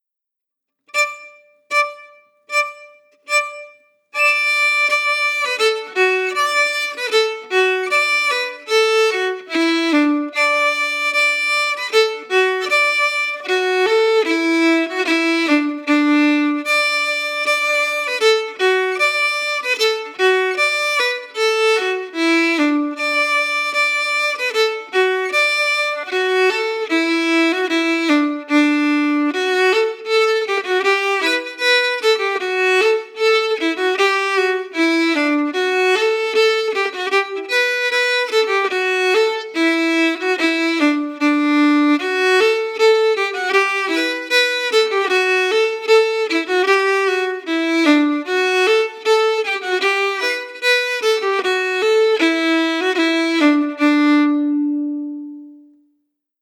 Key: D
Form: Polka